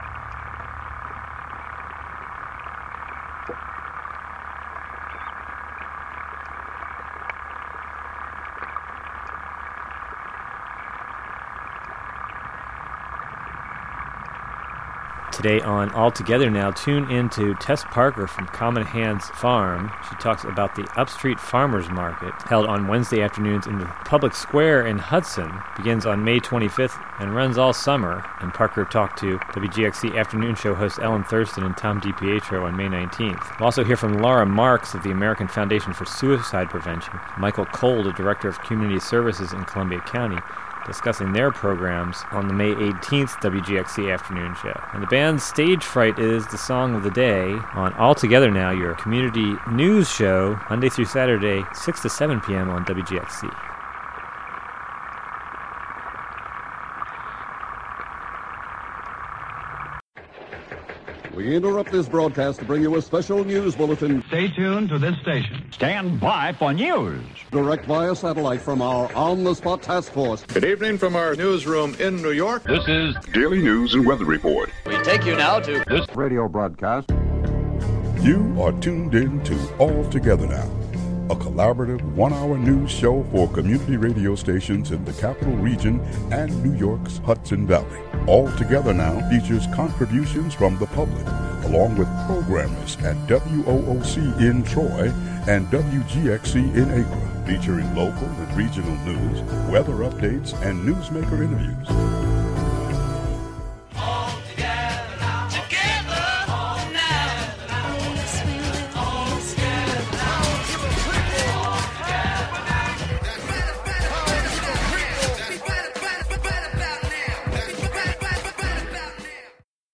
"All Together Now!" is a new evening news show brought to you by WGXC in Greene and Columbia counties, and WOOC in Troy.